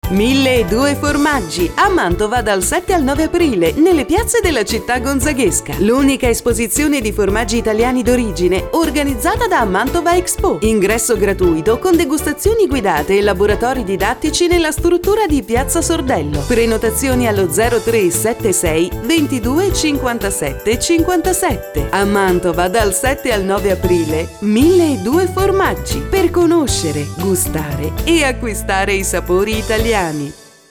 Spot